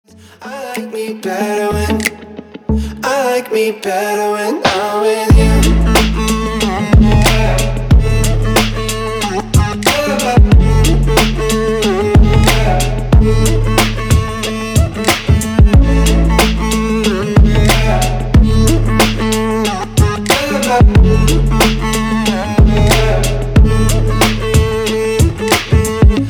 • Качество: 320, Stereo
поп
dance
Electronic
EDM
романтичные